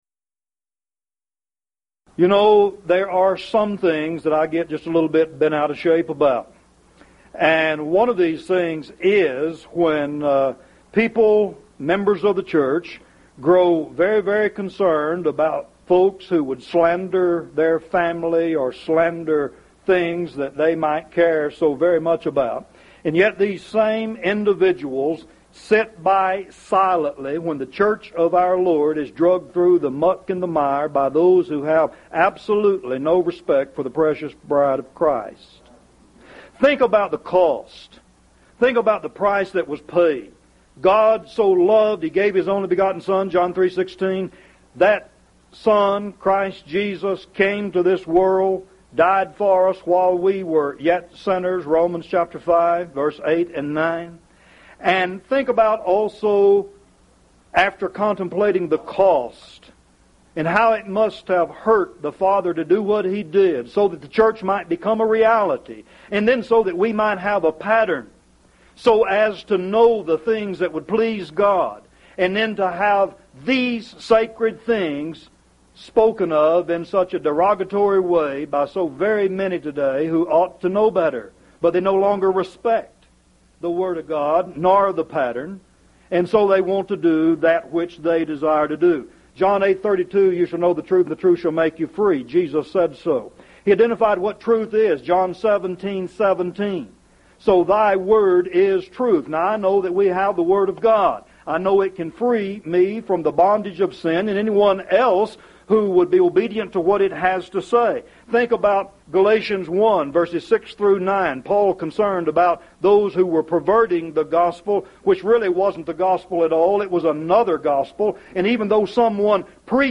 Event: 1st Annual Lubbock Lectures Theme/Title: The Faith Once For All Delivered